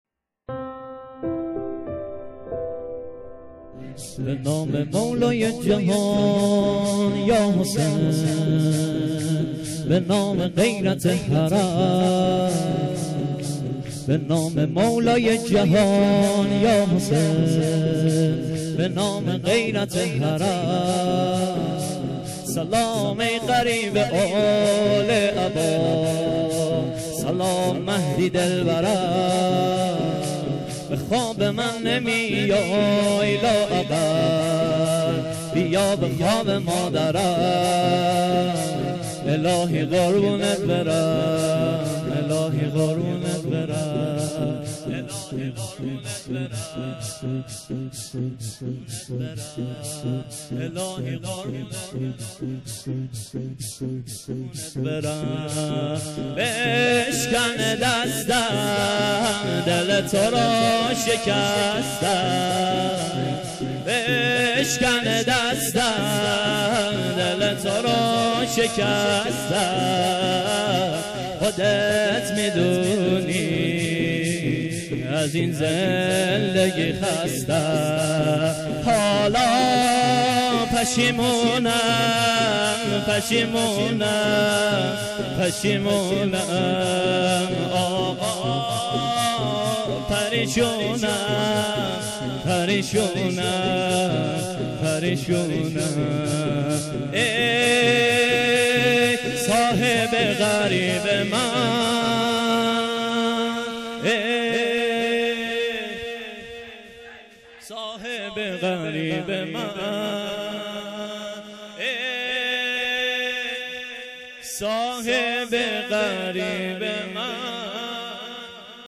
مراسمات هفتگی
روز عاشورا_ شور زیبا